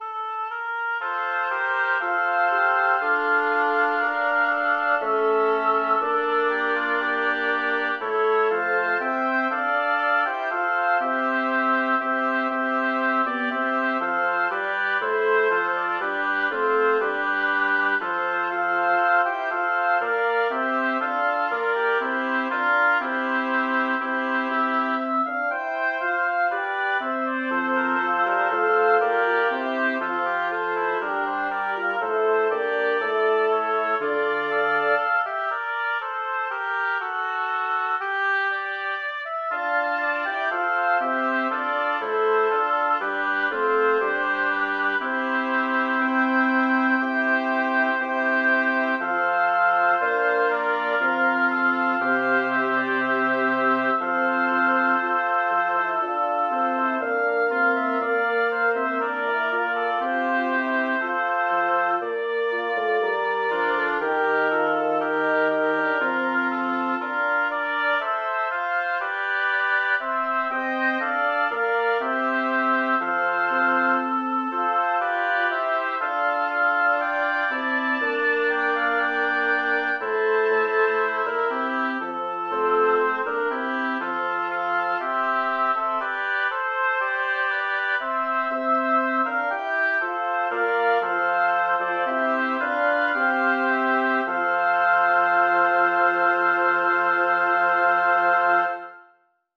Title: Non più guerra, pietate Composer: Philippe de Monte Lyricist: Giovanni Battista Guarini Number of voices: 5vv Voicing: SSAAT Genre: Secular, Madrigal
Language: Italian Instruments: A cappella